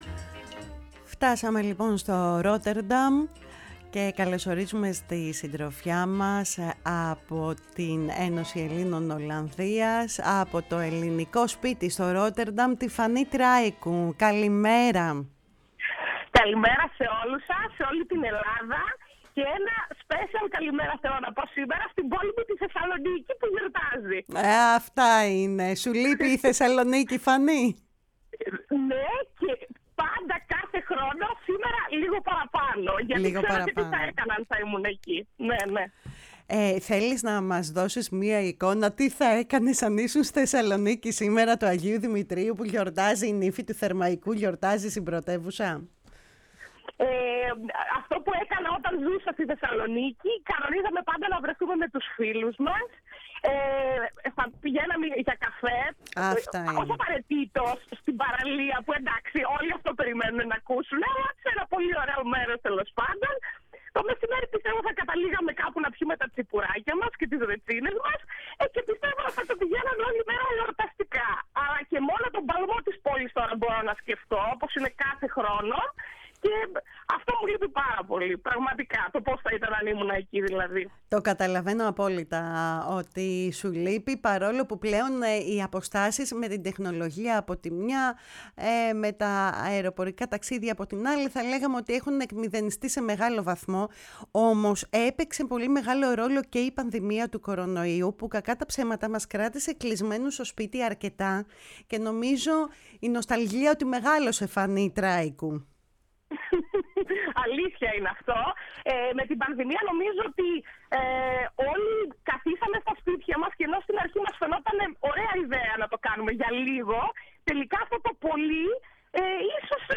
μίλησε στη “Φωνή της Ελλάδας” και συγκεκριμένα στην εκπομπή “κουβέντες μακρινές”